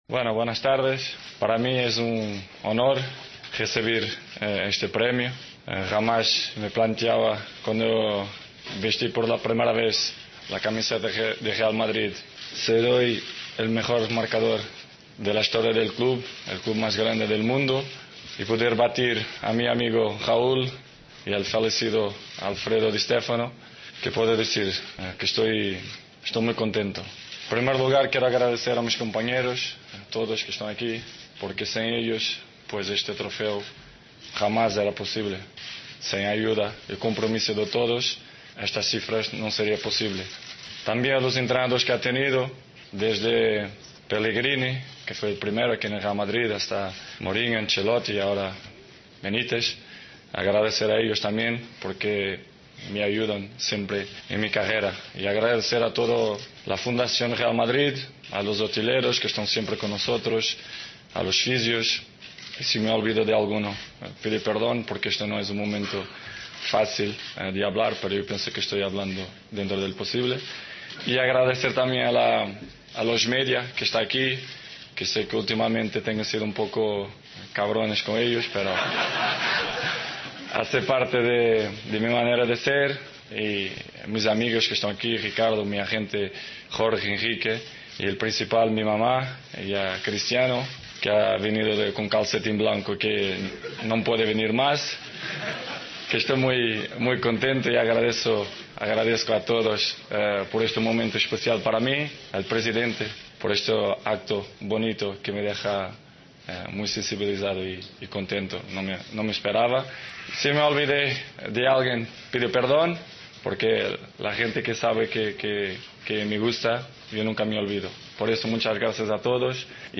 Escucha aquí el discurso de Cristiano Ronaldo